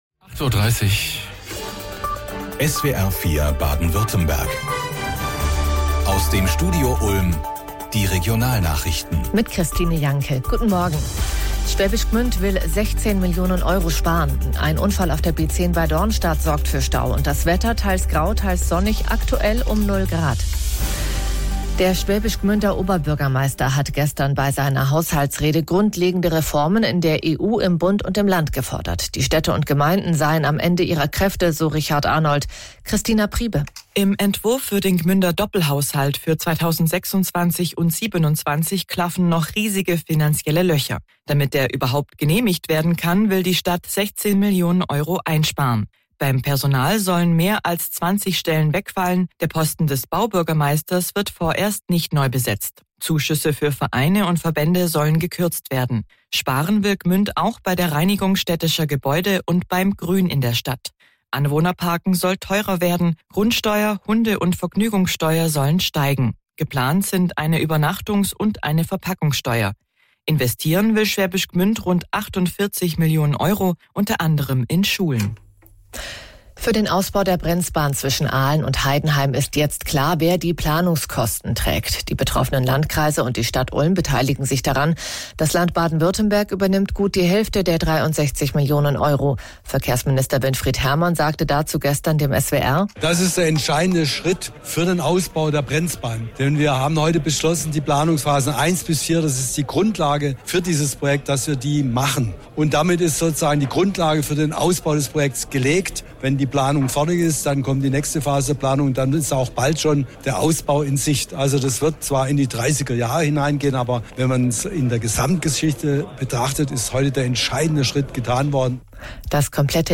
Verkehrsminister Winfried Hermann (Grüne) im SWR-Interview zur Brenzbahn: